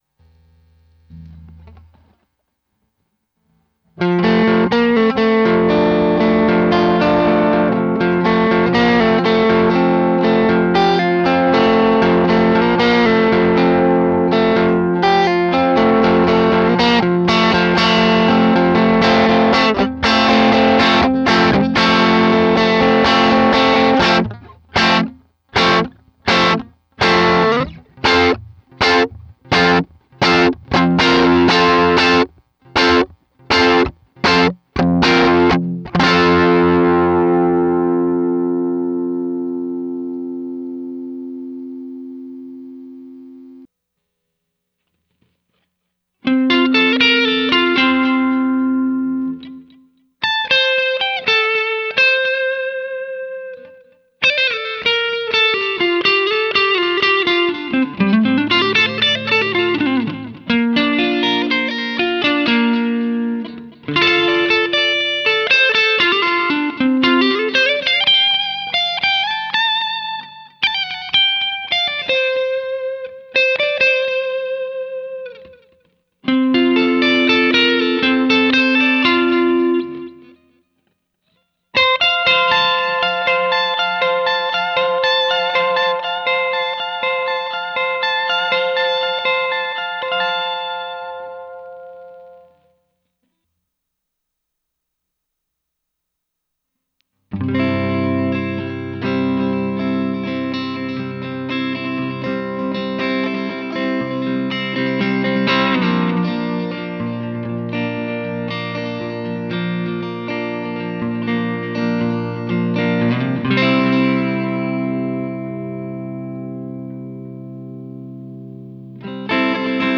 120 BPM
Just riffin on my new tele. 120 bpm